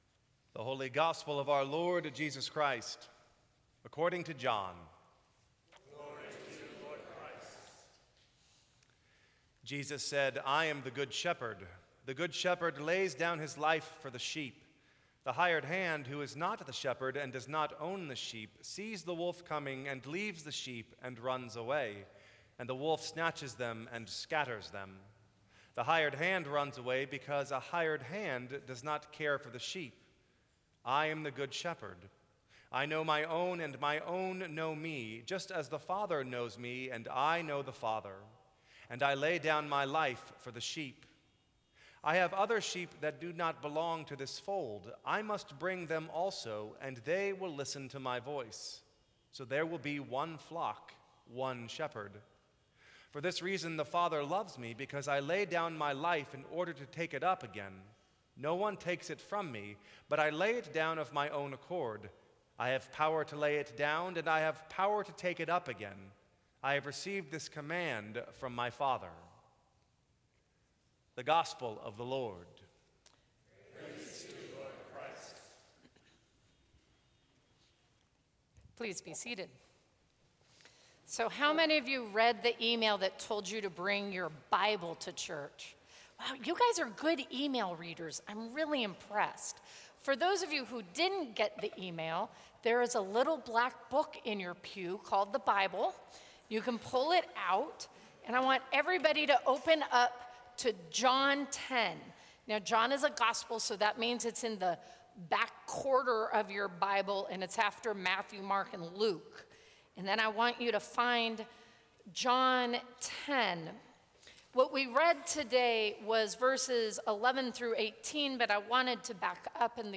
Sermons from St. Cross Episcopal Church A look at the Good Shepherd Sep 25 2015 | 00:16:33 1x 00:00 / 00:16:33 Subscribe Share Apple Podcasts Spotify Overcast RSS Feed Share Link Embed